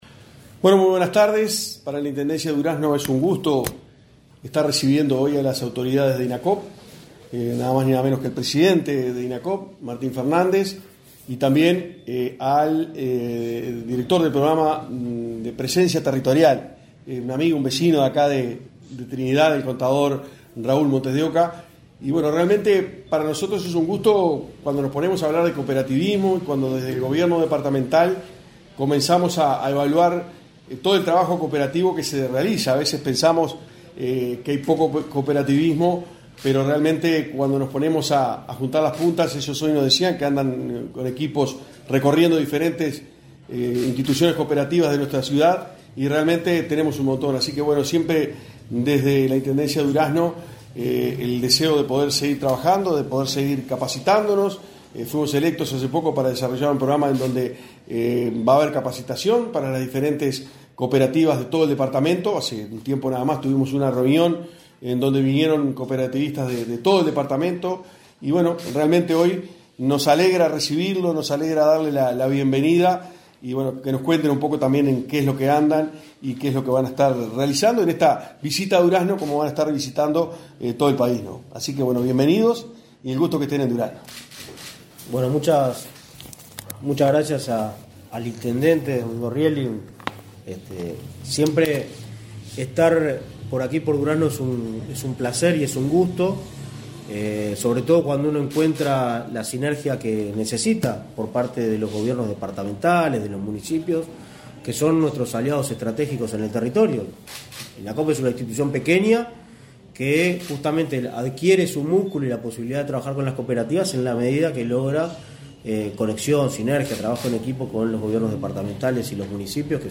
Conferencia de prensa por visita de directorio de Inacoop en Durazno
Conferencia de prensa por visita de directorio de Inacoop en Durazno 23/08/2023 Compartir Facebook X Copiar enlace WhatsApp LinkedIn Autoridades del Instituto Nacional del Cooperativismo (Inacoop) recorrieron, este 23 de agosto, locaciones de emprendimientos en el departamento de Durazno. Tras la visita, se expresaron en conferencia de prensa el presidente del Inacoop, Martín Fernández; el director departamental de Convivencia Territorial, Raúl Montes de Oca, y el secretario general de la intendencia departamental, Andrés Lesa.